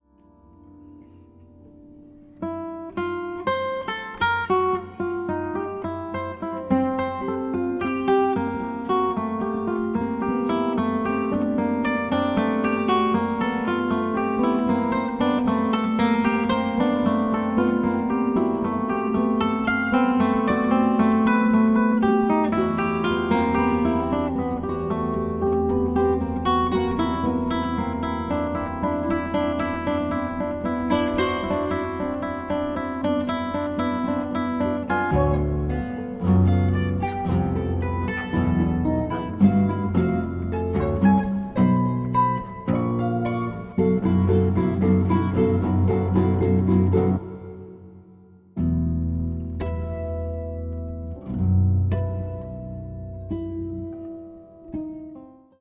16-string classical guitar